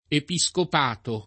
[ epi S kop # to ]